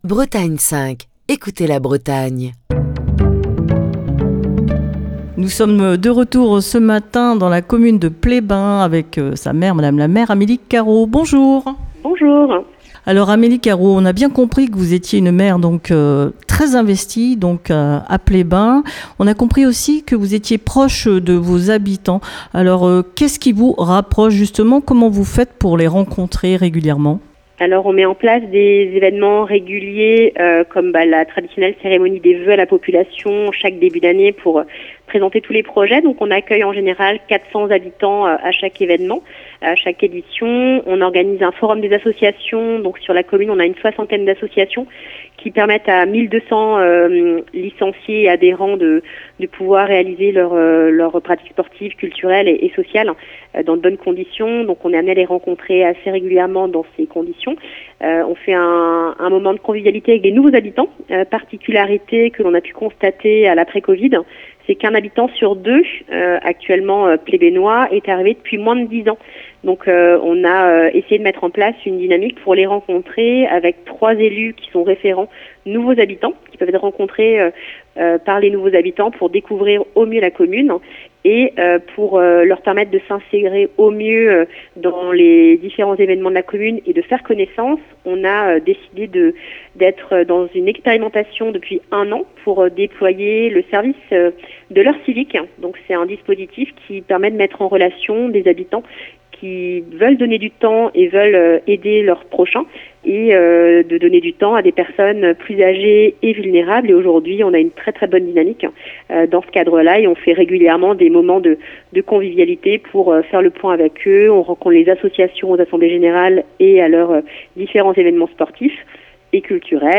Cette semaine, Destination commune pose ses micros dans le Finistère à Pleyben.